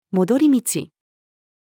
戻り道-the-way-back-female.mp3